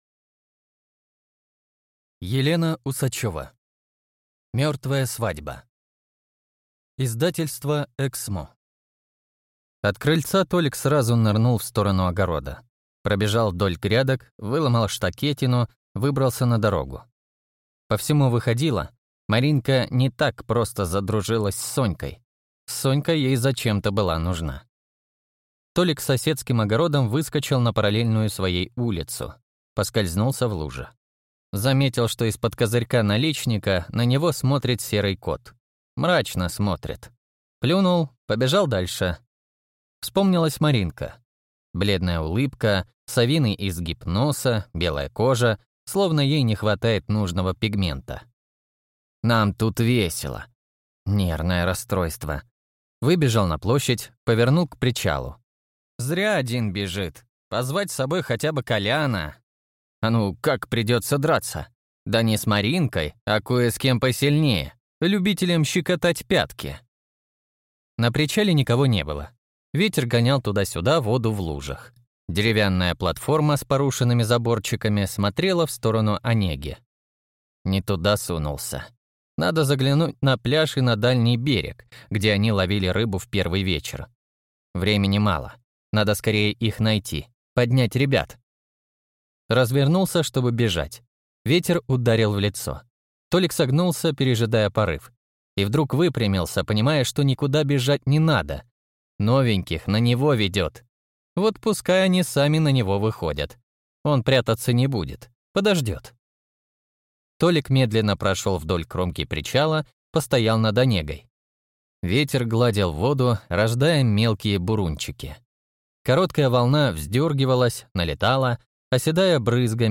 Аудиокнига Мёртвая свадьба | Библиотека аудиокниг